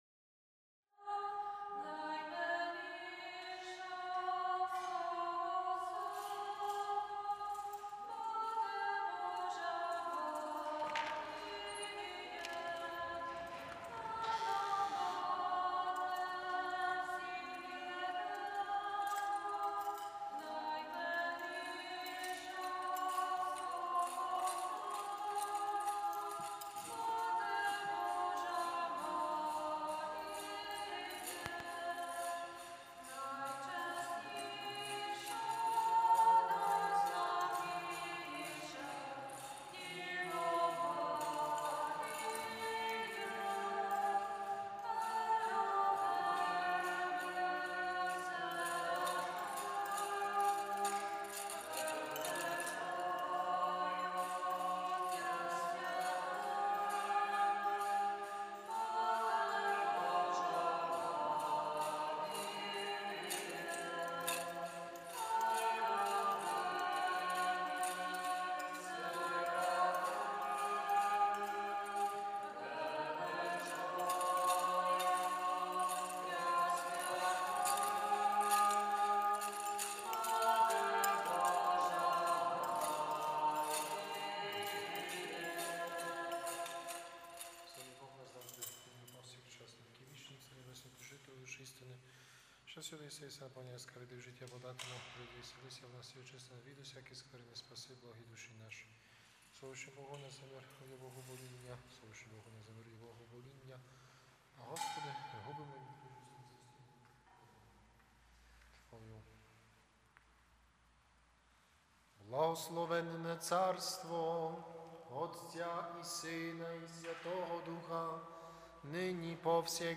З нашої церкви – Cвято Благовіщення 07 Квітня 2020 Божественна Літургія Івана Золотоустого